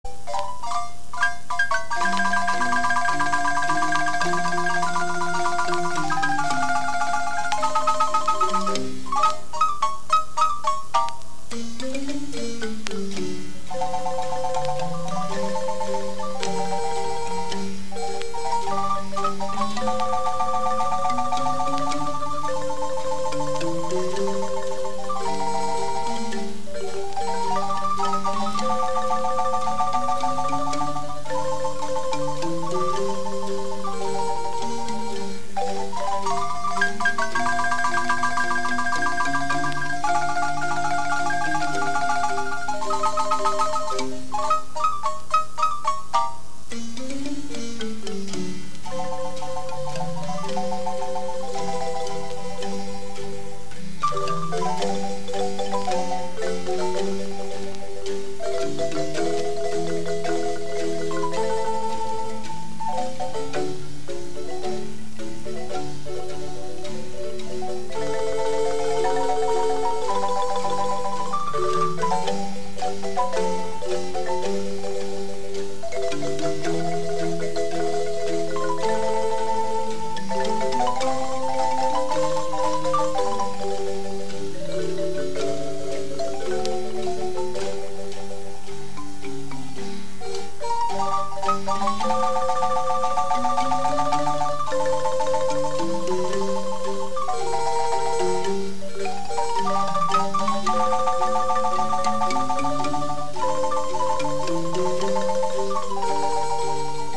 Música guanacasteca: marimba